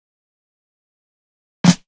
Fill 128 BPM (13).wav